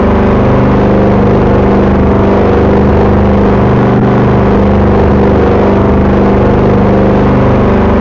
SCarEngineSounds